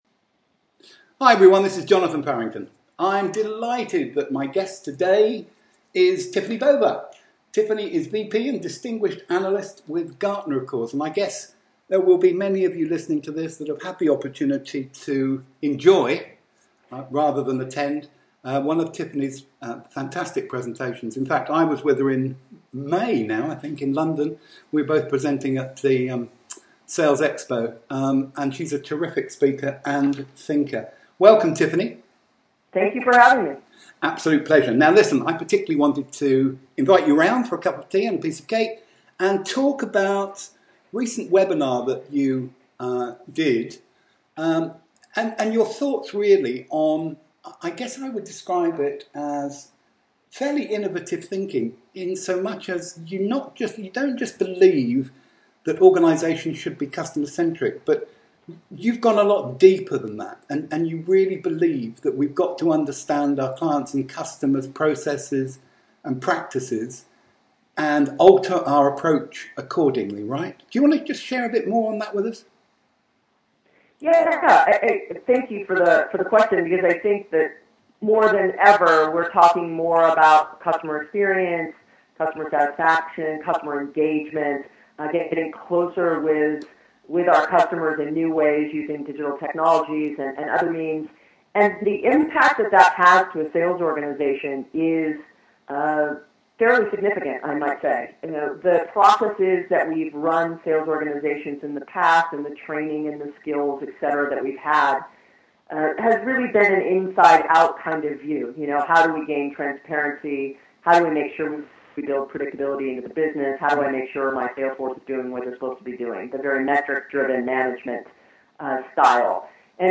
In Conversation
We think you will really enjoy this dialogue between two genuine thought leaders.